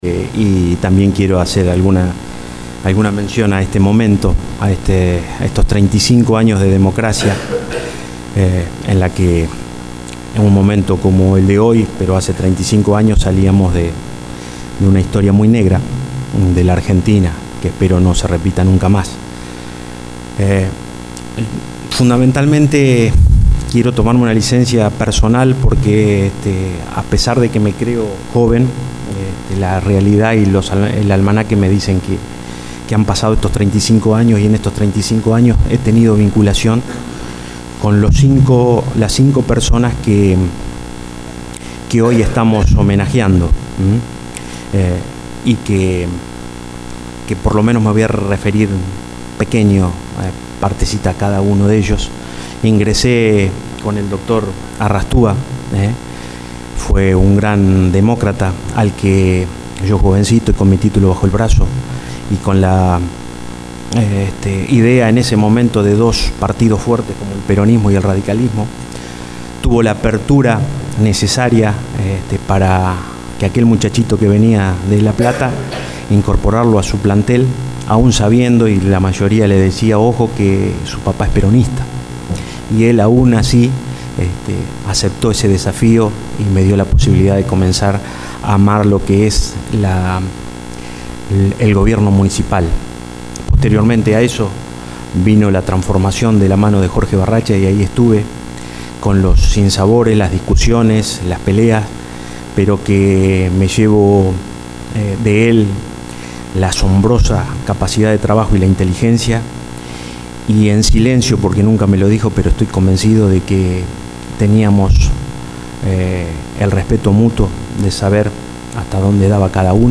En el Acto por el día de la Restauración de la Democracia, en el Consejo Deliberante se realizó un homenaje a todos los Intendentes que gobernaron desde el año 83 a la fecha y tomo la palabra los el Presidente de Honorable Consejo Deliberante Claudio Figal.
Claudio-Figal-acto-dia-democracia.mp3